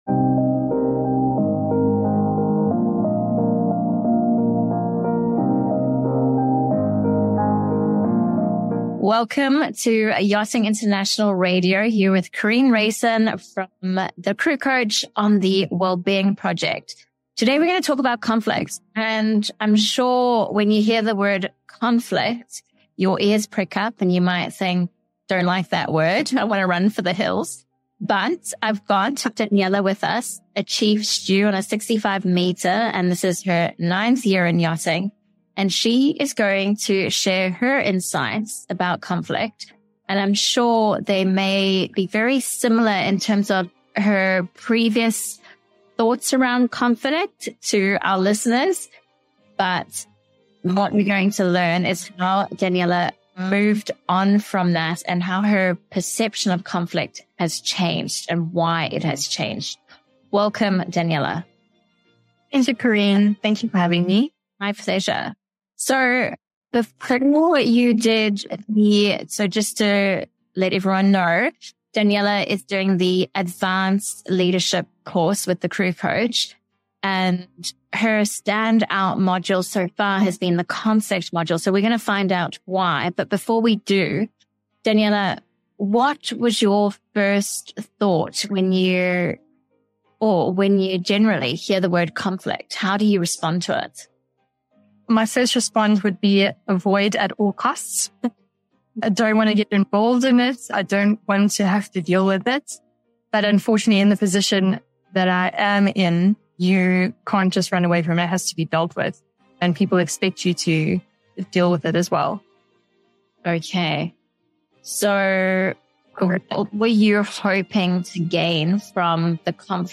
In this interview, we discuss the underlying causes of conflict and learn appropriate tools and strategies to prevent and resolve it.